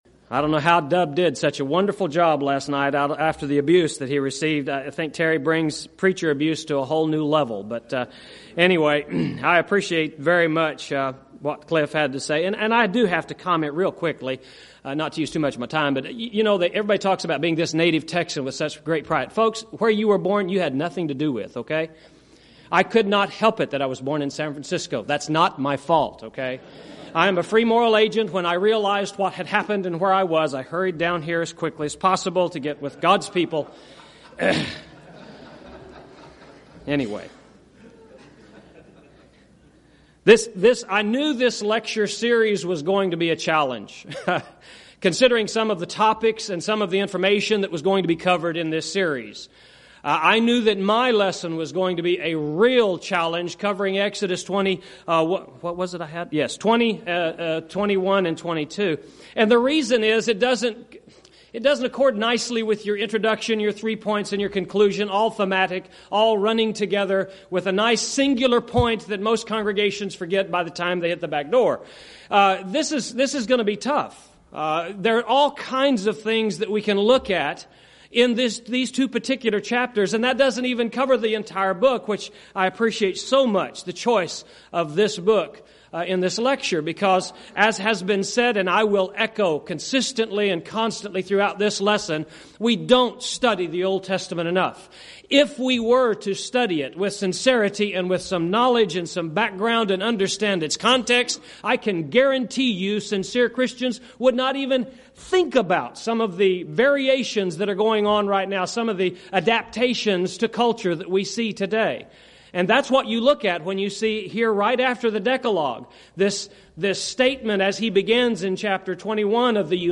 Event: 2nd Annual Schertz Lectures Theme/Title: Studies In Exodus